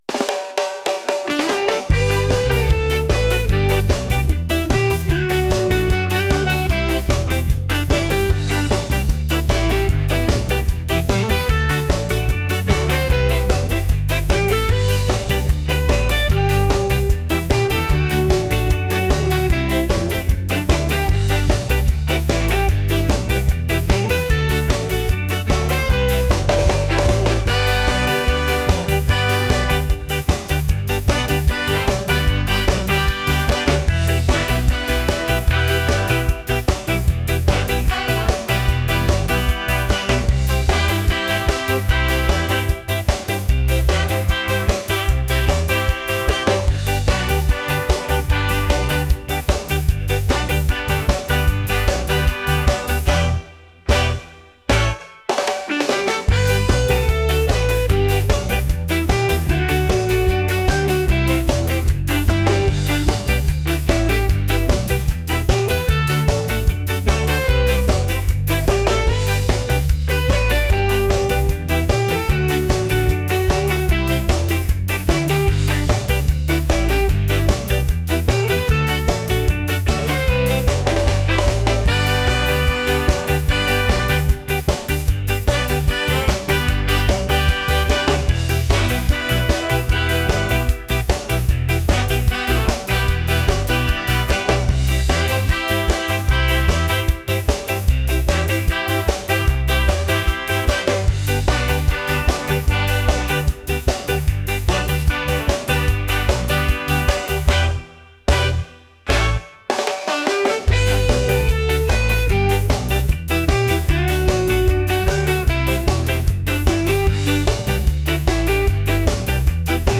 ska | upbeat | reggae